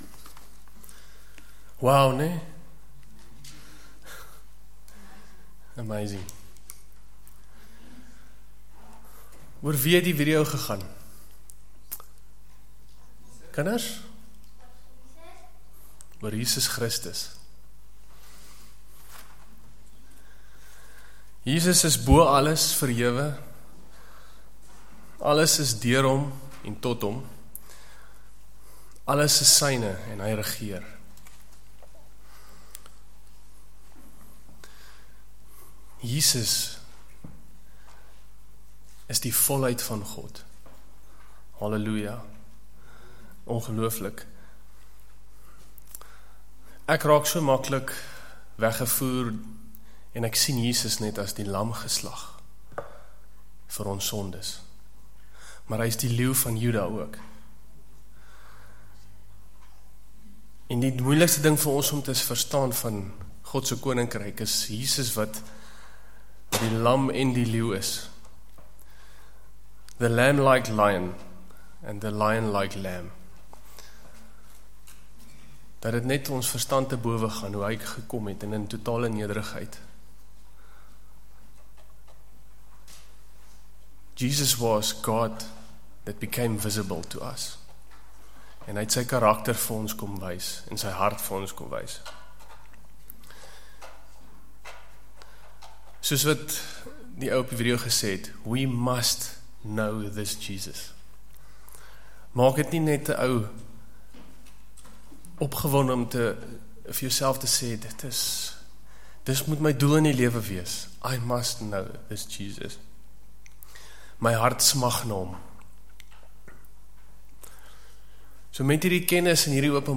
Ope bediening bydae deur verskeie mense tydens woord bediening tyd